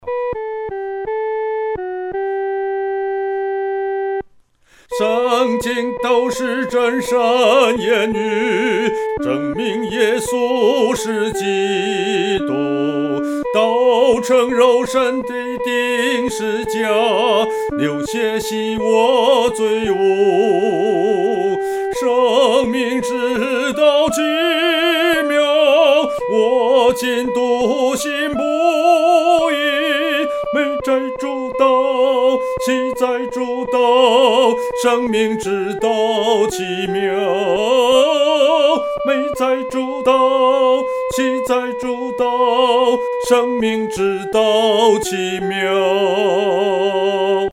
独唱（第一声）